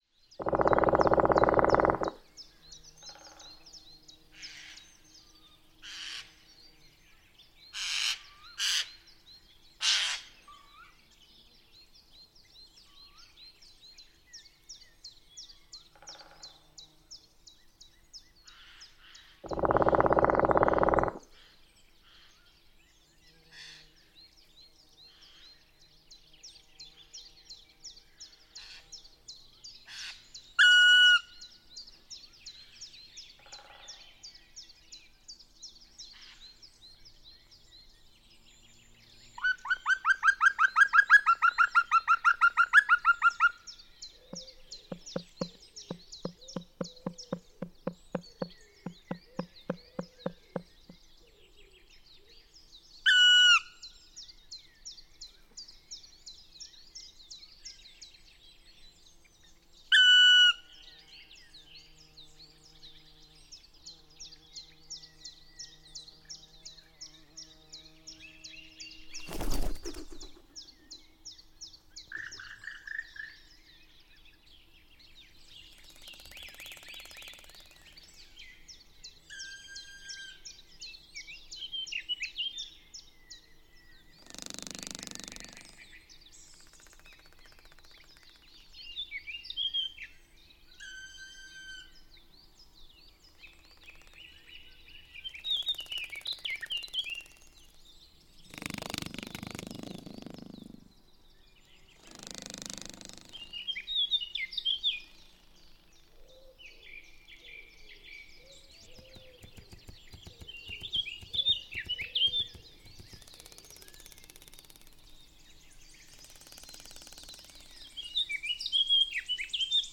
Il s’agit d’un montage qui s’inscrit dans une année, réduite à environ cinquante-quatre minutes, sans aucun bruit de moteur, même à l’horizon. L’assemblage, l’enchaînement et le mixage des éléments qui la composent sont conçus pour susciter une sensation d’immersion dans l’étendue forestière et l’intimité de certains de ses habitants non-humains.
Il regroupe des vocalises, stridulations ou percussions d’origine animale (71 espèces) collectées sur plusieurs années et classées selon un indice d’abondance simplifié.